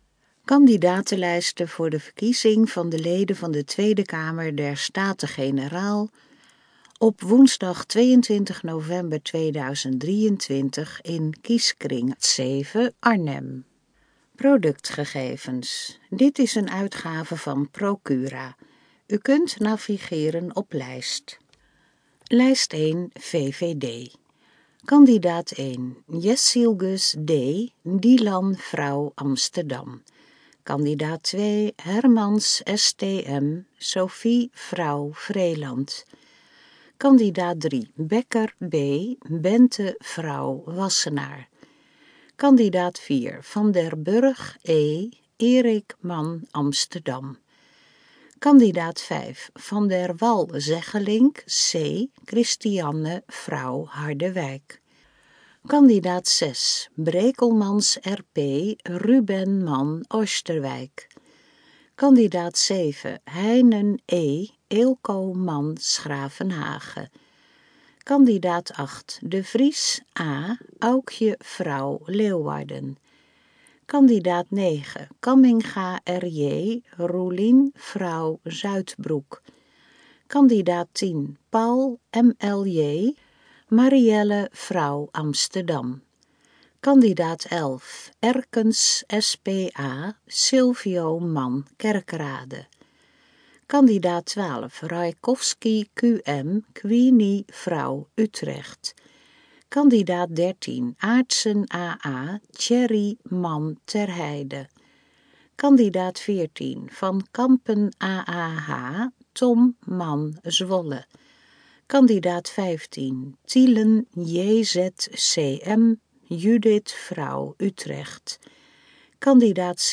HAH_in_gesproken_vorm_TK23_Kieskring_7_Arnhem.mp3